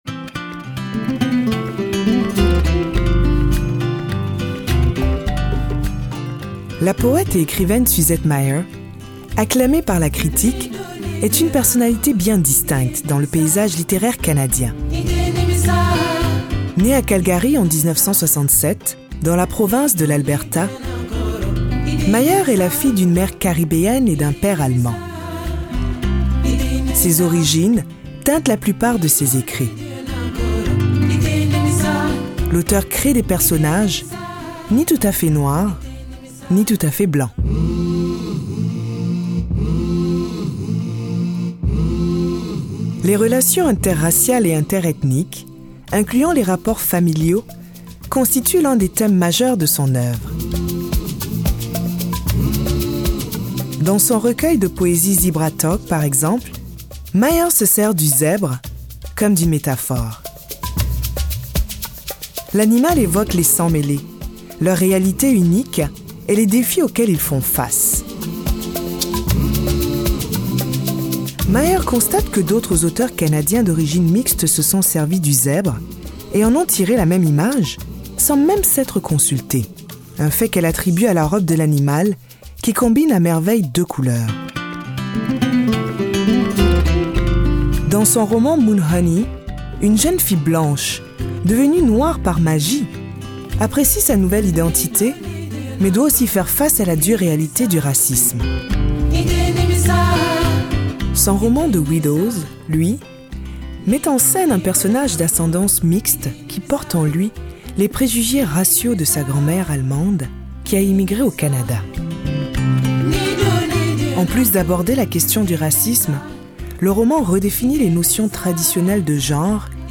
Narratrice: